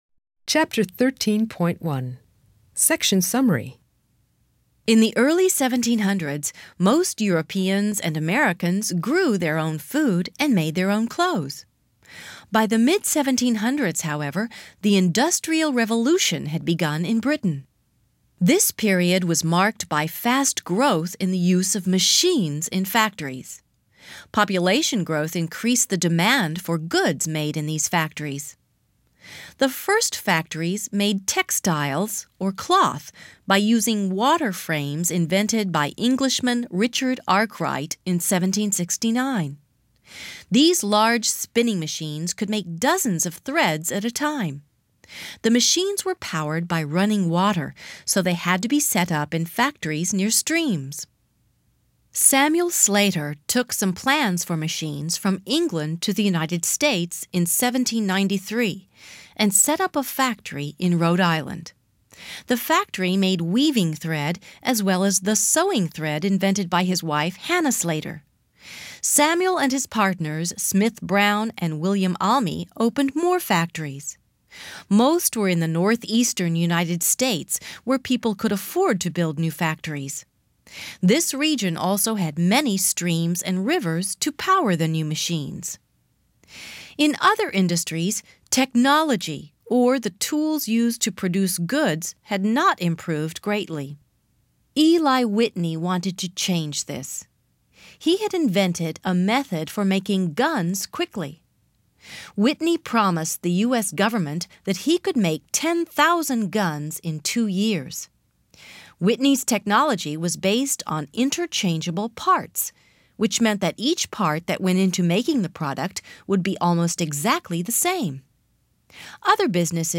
AUDIO TEXTBOOK